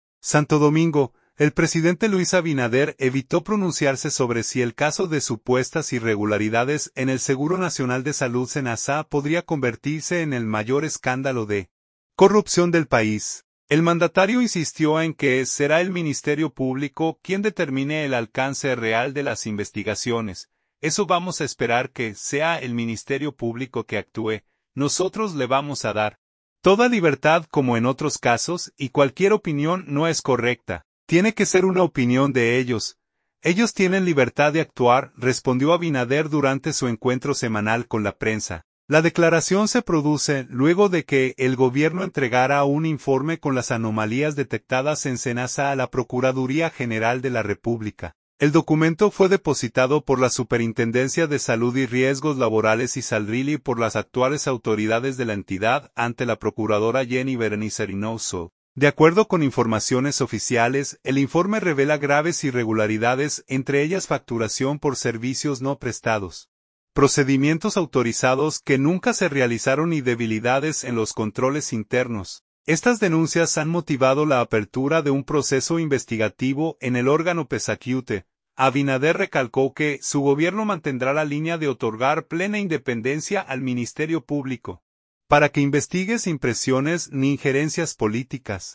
“Eso vamos a esperar que sea el Ministerio Público que actúe. Nosotros le vamos a dar toda libertad, como en otros casos, y cualquier opinión no es correcta. Tiene que ser una opinión de ellos. Ellos tienen libertad de actuar”, respondió Abinader durante su encuentro semanal con la prensa.